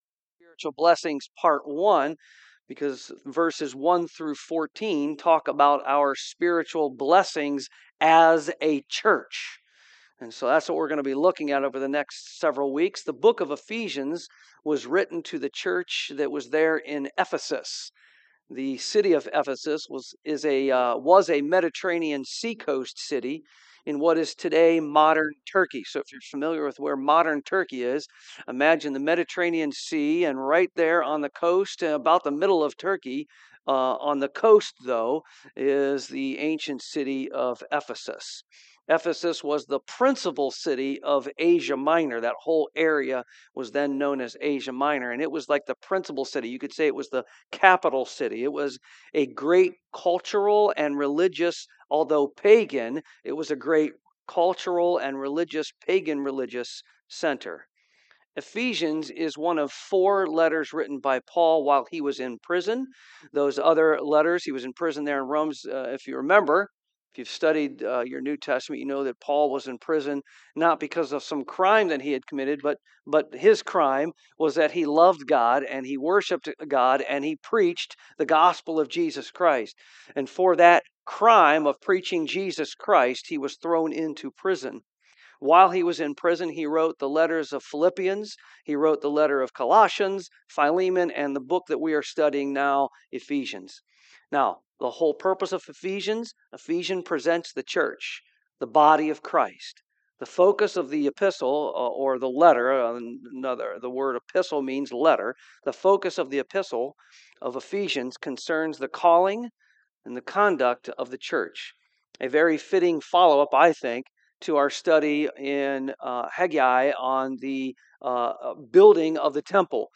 Ephesians 1:1-5 Service Type: AM We are the body and bride of Christ